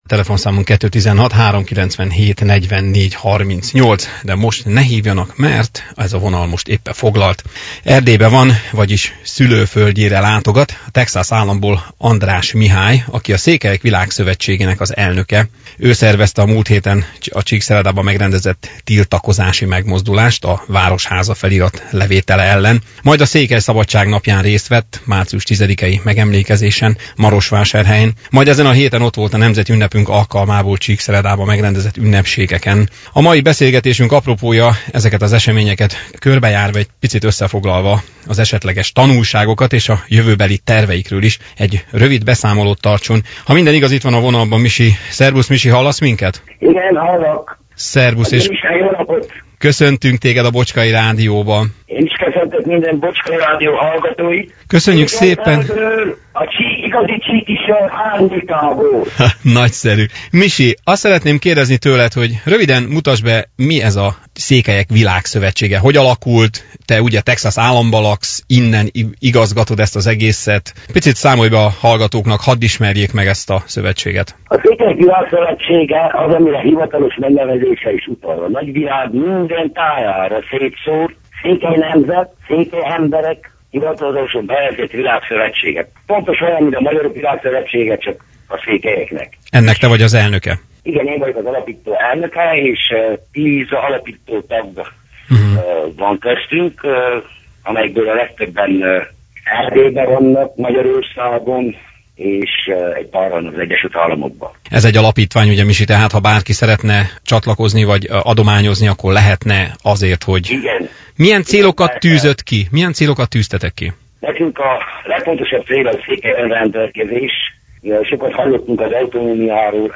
Interjú
A múlt vasárnapi adásunk alatt telefonos interjún keresztül lehetőségünk nyílt